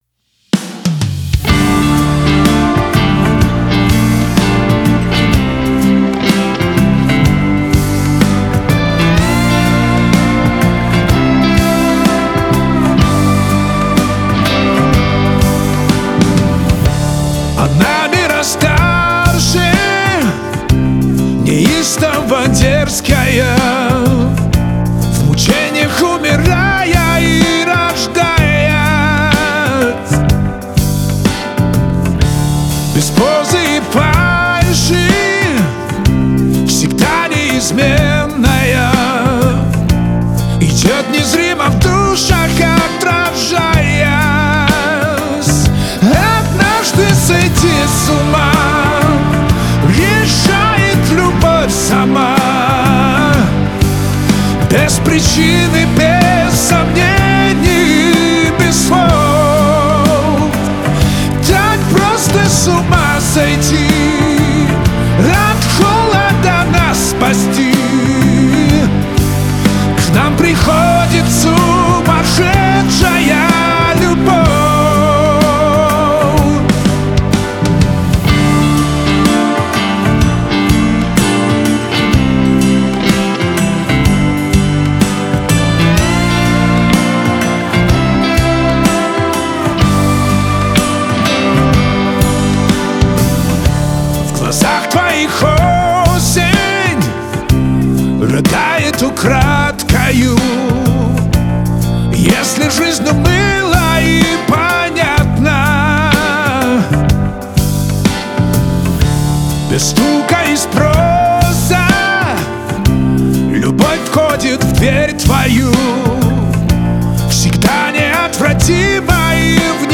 Категория Рэп